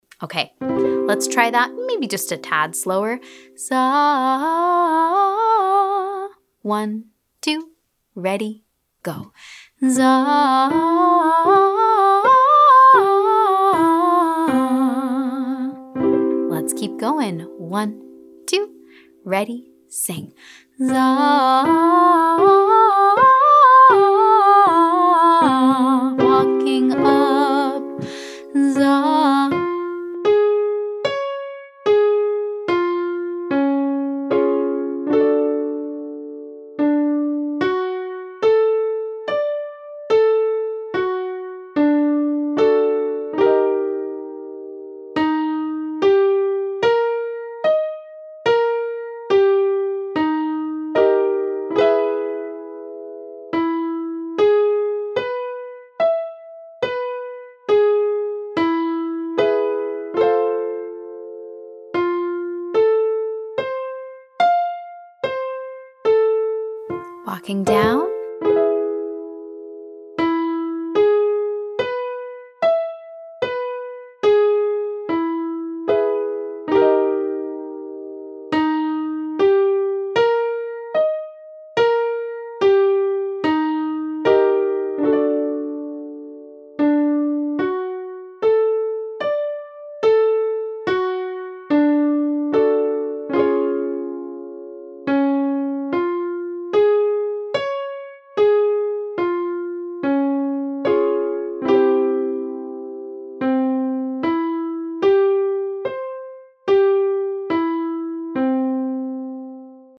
Exercise 3: Zah jumping arpeggio with turnaround 2 (121, 343, 565, 898, 565, 343, 1)
This next one involves more jumping and a lot more turnarounds.